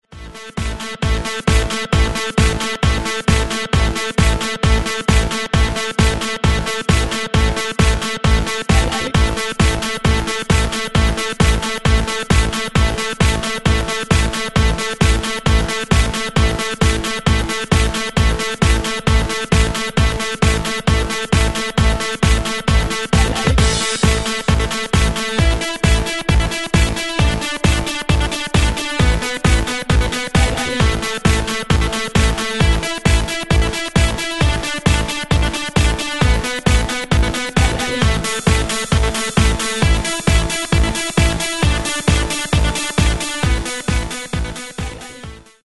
Styl: Electro, Techno